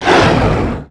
hellhound_die.wav